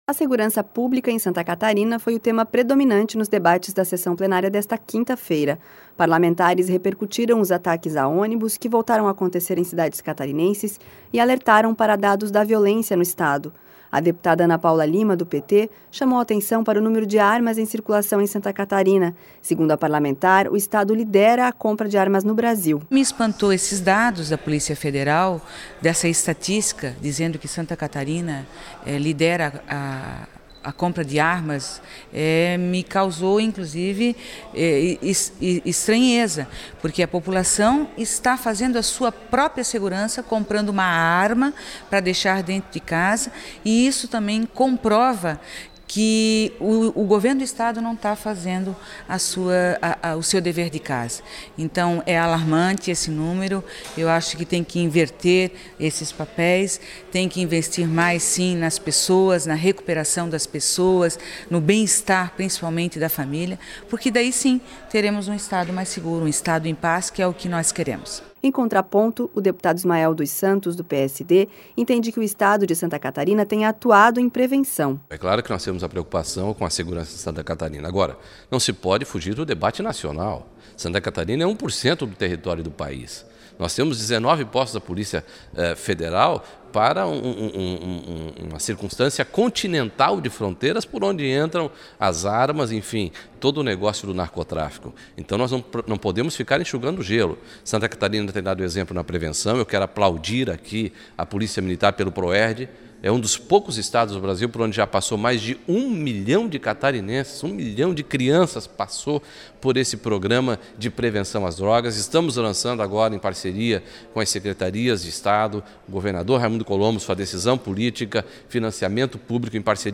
Entrevistas com: deputada Ana Paula Lima (PT),  deputado Ismael dos Santos (PSD) e deputado Maurício Eskudlark (PSD)